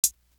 Closed Hats
Lose Ya Life Hat.wav